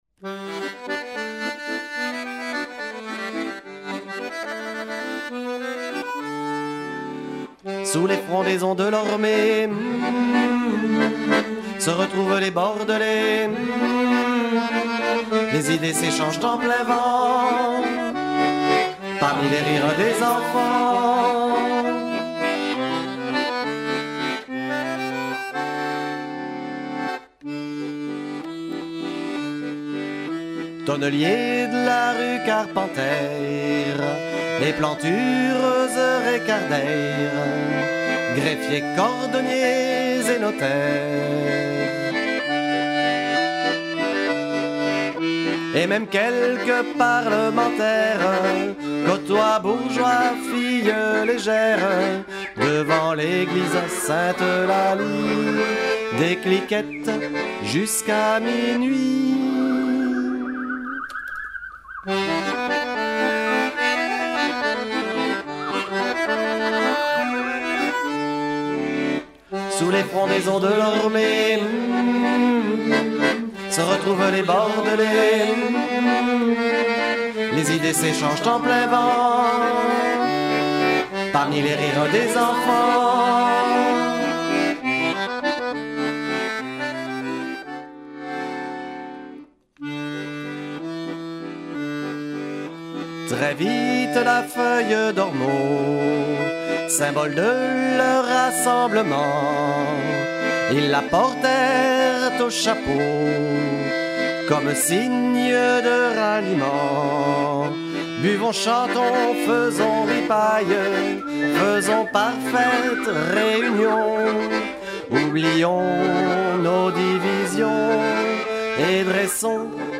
Musiques composées à l’accordéon, jouées et chantées en direct par les comédiens